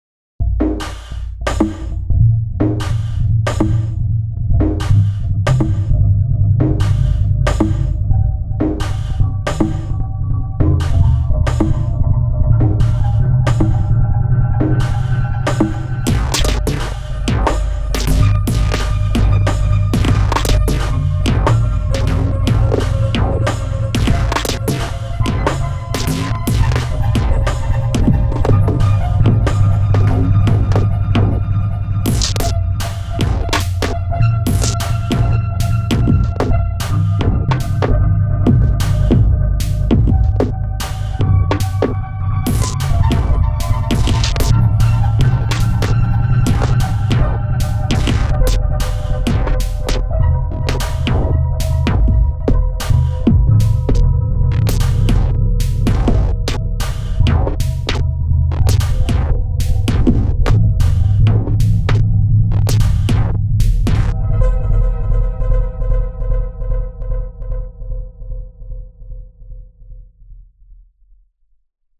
Just got myself a ringmod (Jeweler cast) as heard in my clip above.